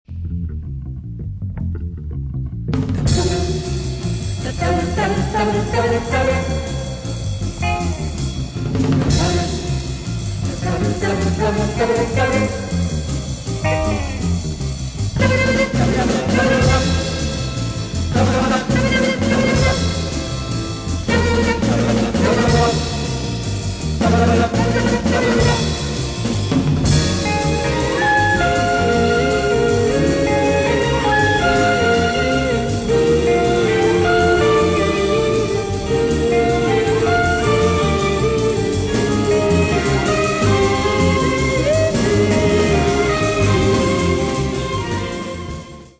nervous fast instr.